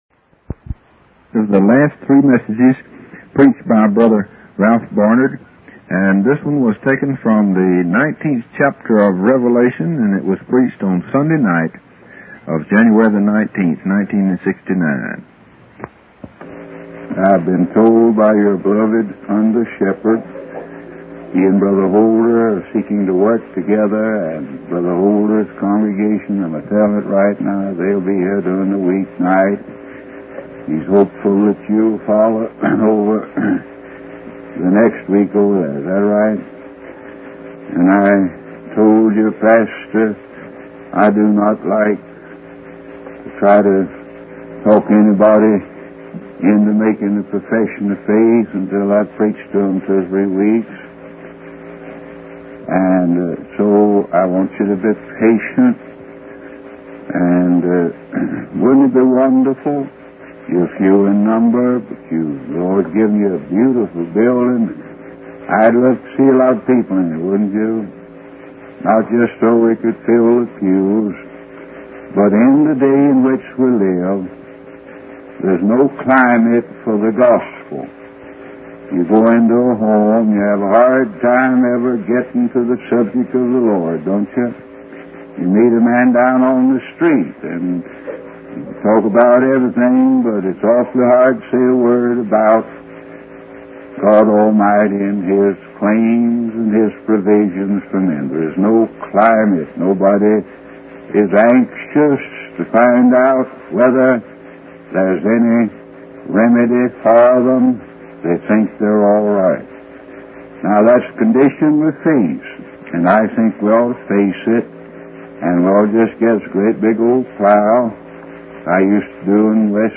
In this sermon, the preacher emphasizes the importance of recognizing the severity of sin and the need for salvation through Jesus Christ. He challenges the audience to believe in the punishment of sin and the necessity of God's wrath.